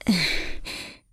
sigh.wav